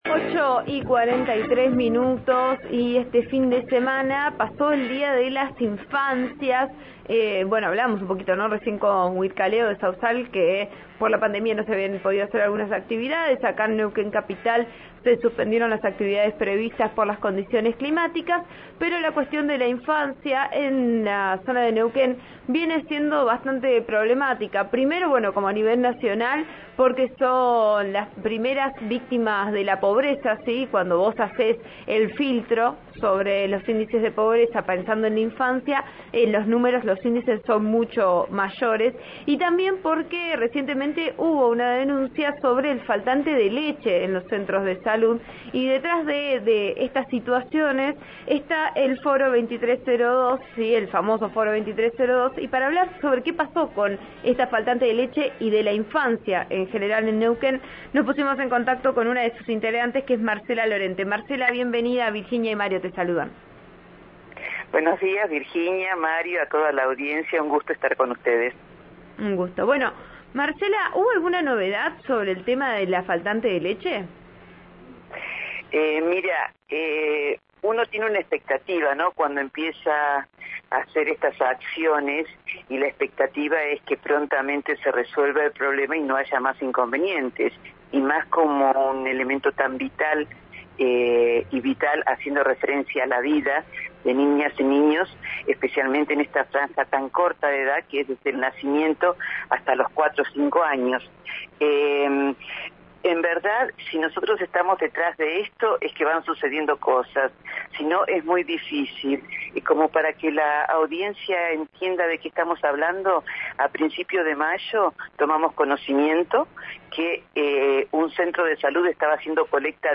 En diálogo con «Vos A Diario» (RN RADIO 89.3)